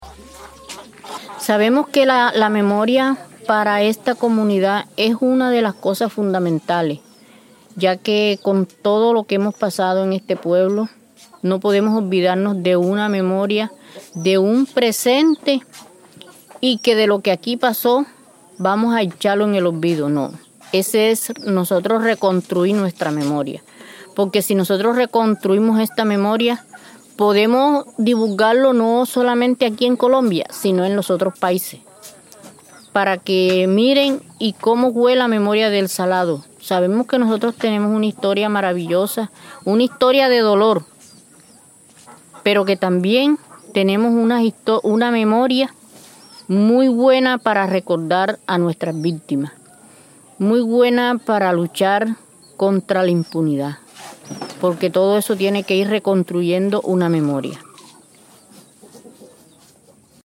Un recorrido por la memoria que evoca el dolor, la resistencia, la esperanza y las reivindicaciones de las víctimas que ahora alzan su voz frente a la historia que las silenció. Su memoria se narra a partir de sonidos propios del folclore montemariano, como el vallenato y las décimas, y también desde la poesía y los testimonios que interpretan los relatos de los victimarios.